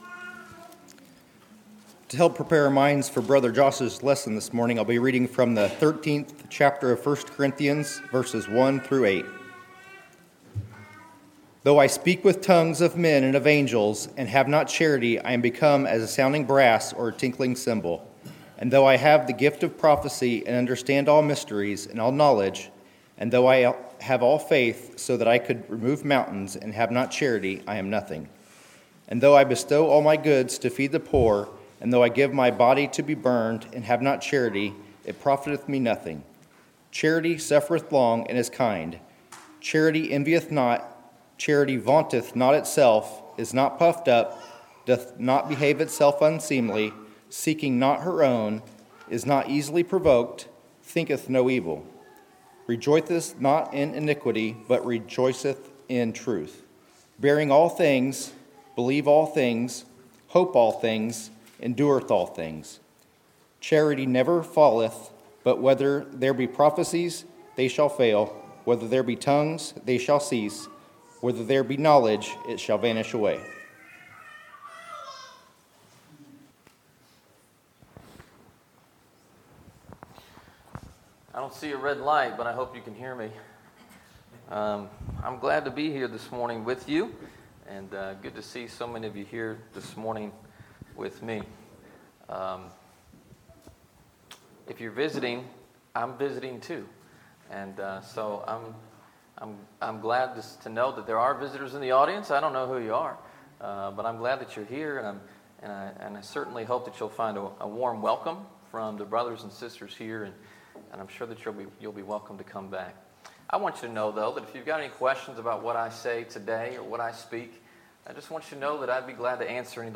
Sermons, October 29, 2017